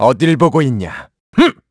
Riheet-Vox_Skill4_kr-02.wav